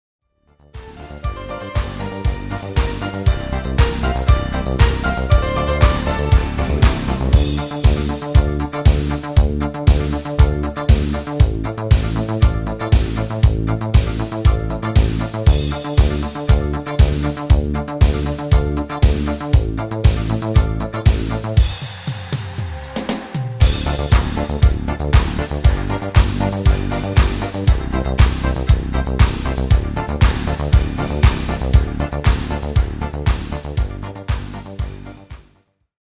Demo/Koop midifile
Genre: Pop & Rock Internationaal
- Géén vocal harmony tracks
Demo's zijn eigen opnames van onze digitale arrangementen.